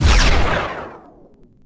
EnemyBeams.wav